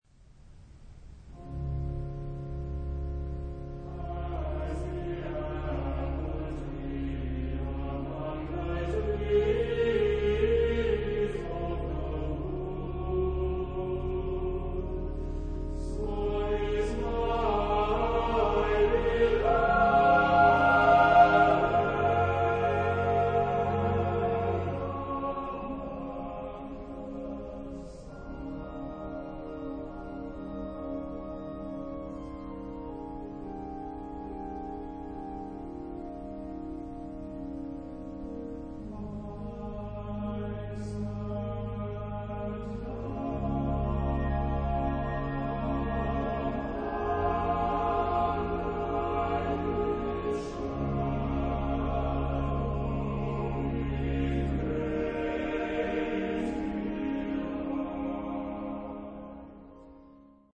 Instrumente: Orgel (1)